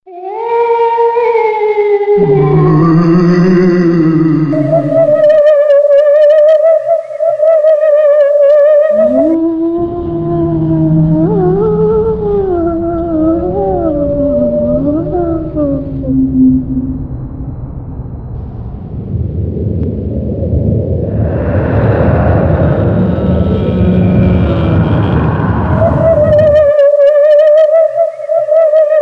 Horror Botón de Sonido